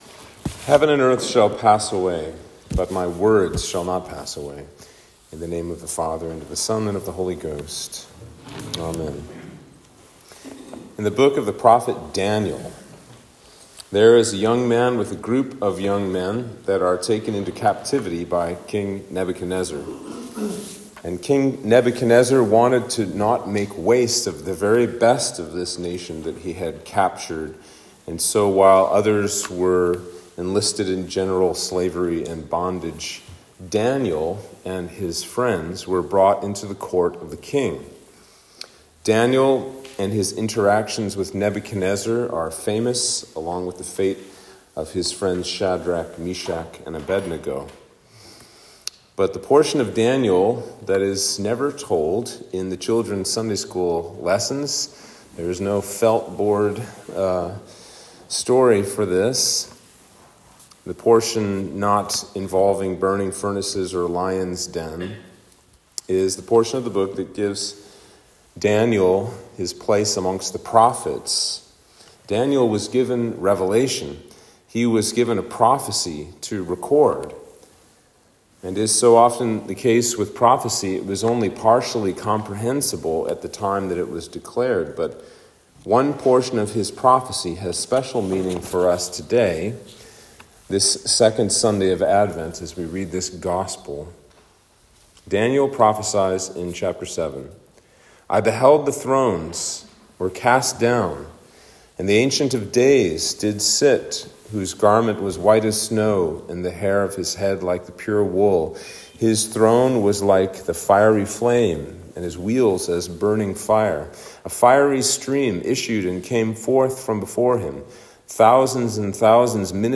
Everything Preached at St. George the Martyr Anglican Church in Simpsonville, SC
Sermon for Advent 2